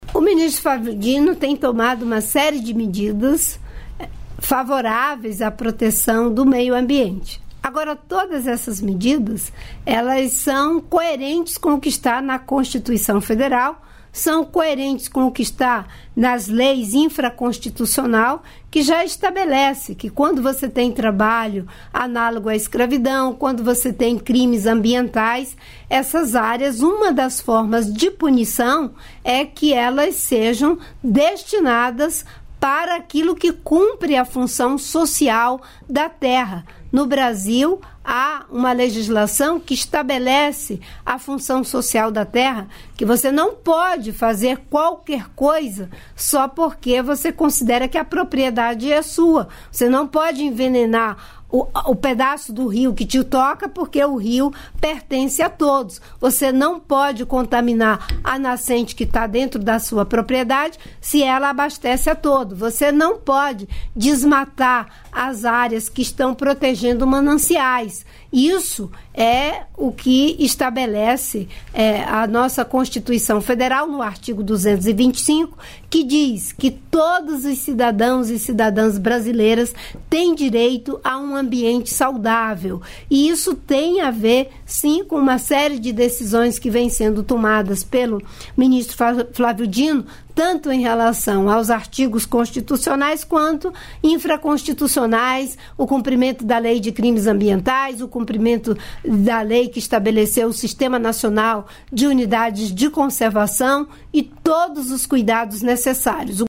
Trecho da participação ministra do Meio Ambiente e Mudança do Clima, Marina Silva, no programa "Bom Dia, Ministra" desta segunda-feira (05), nos estúdios da EBC em Brasília (DF).